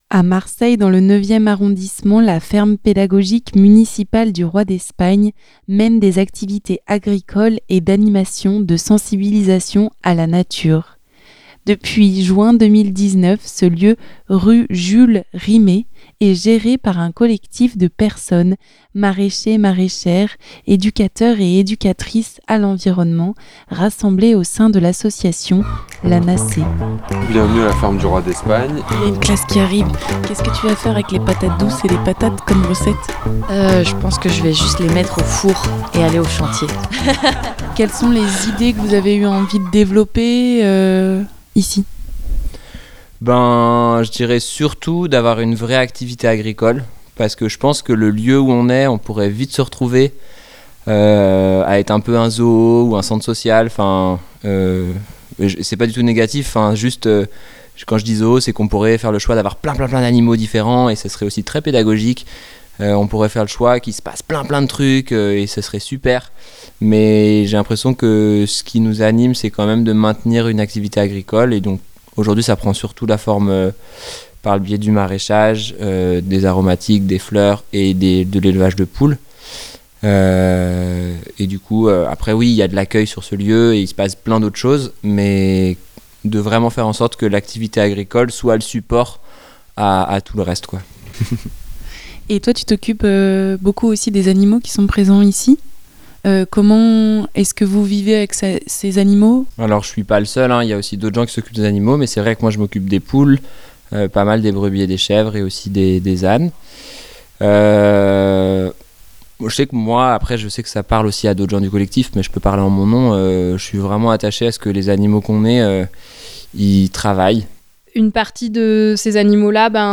Lors d'un de ces chantiers, les salarié.e.s ont été interrogés et du son a été pris pendant l'accueil et les différentes tâches effectuées avec les bénévoles et les enfants d'une classe.